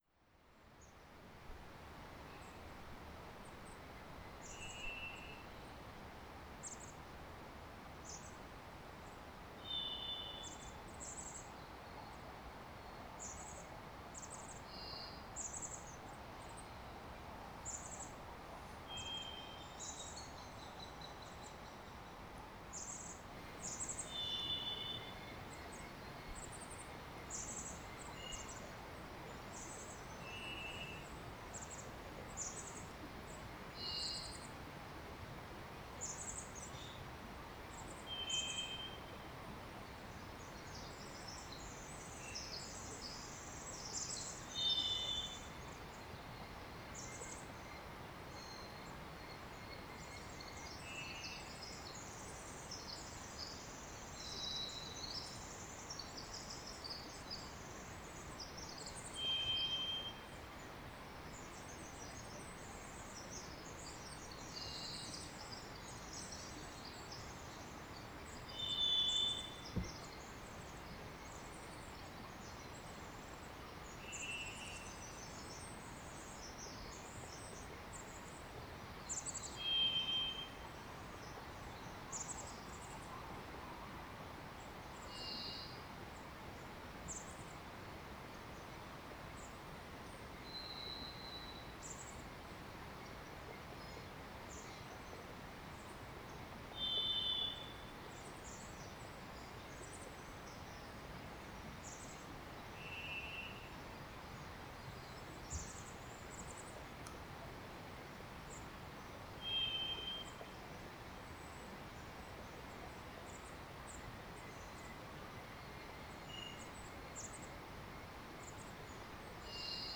Recordings from the trail through the beautiful old growth forest at Fillongley Provincial Park on Denman Island with the bird- and creek-sounds in early spring 2022.
3. Bird sounds – Thrush, Merlin, Wren, Chickadee, Raven
I think we also hear Merlin, Perhaps a juvenile and parent calling to one another.